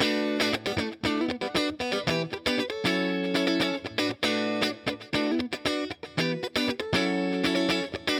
11 Rhythm Guitar PT1.wav